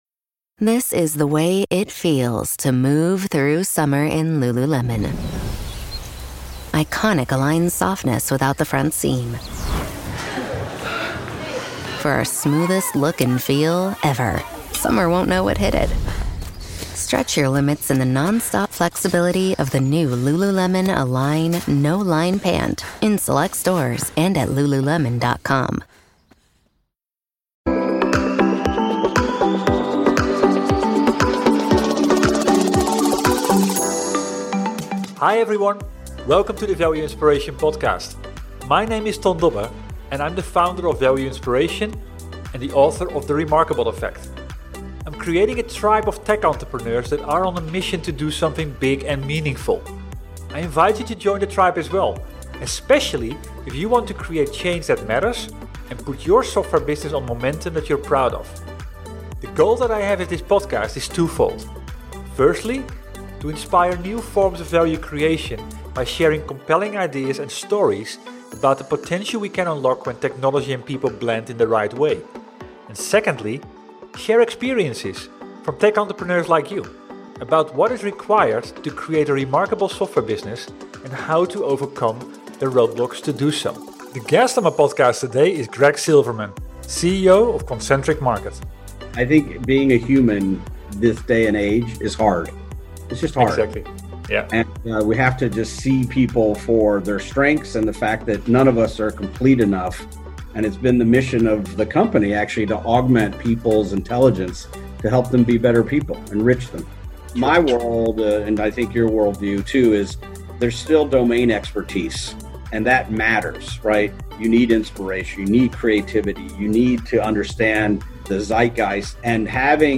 This podcast interview focuses on technology and process that brings people together to deliver transformative impact.